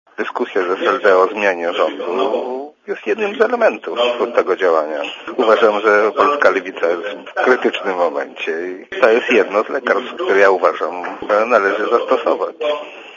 Mówi Tomasz Nałecz